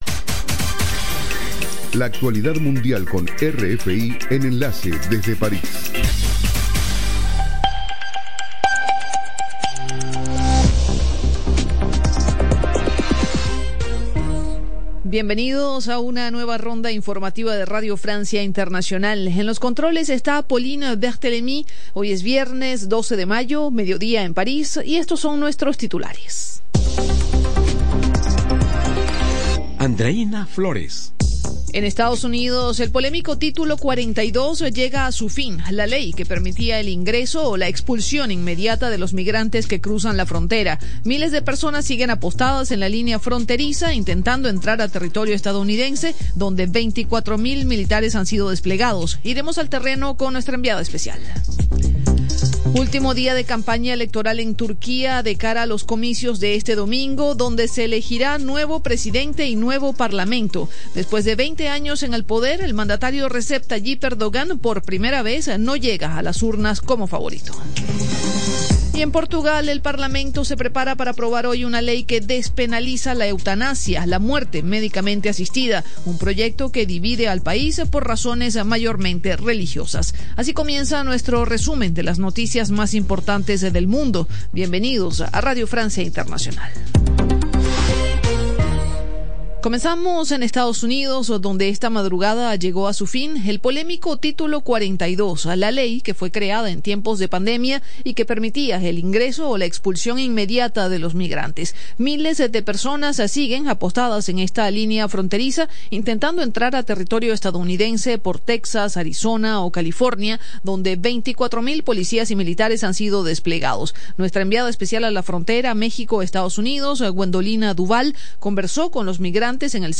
Programa: RFI - Noticiero de las 07:00 Hs.